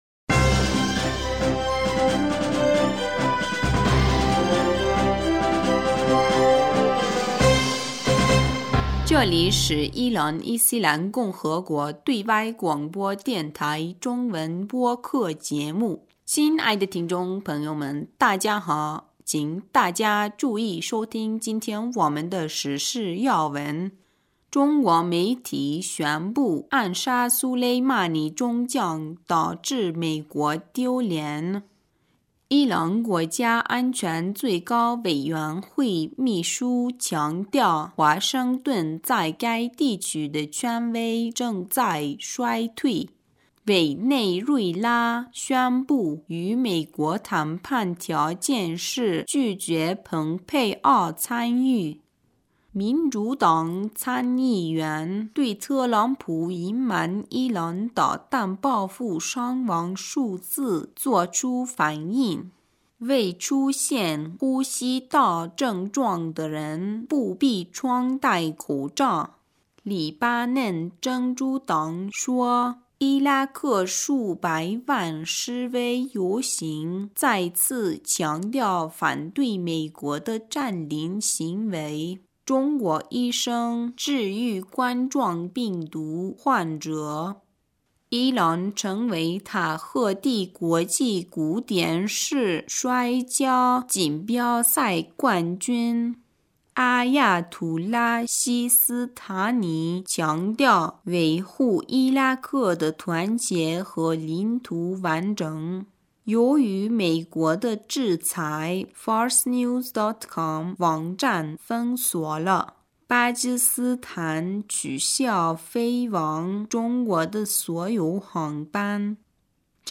2020年1月25日 新闻